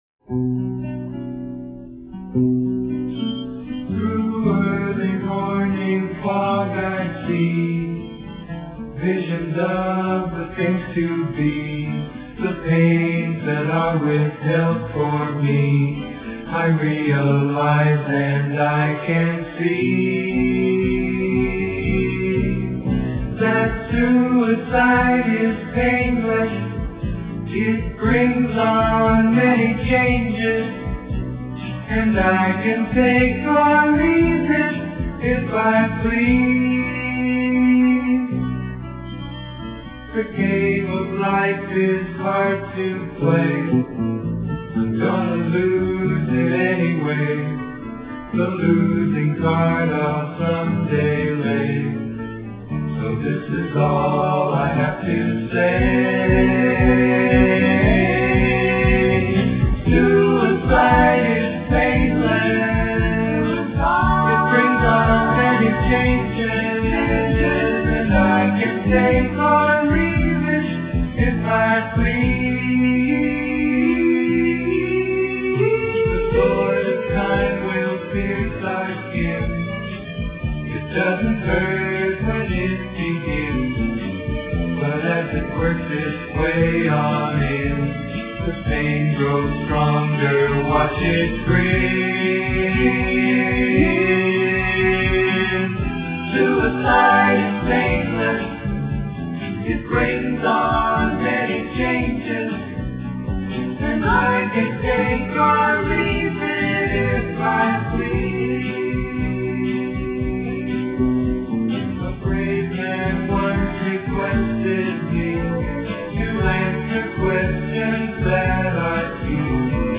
Suicide is Painless (song)